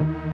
Freq-lead06.ogg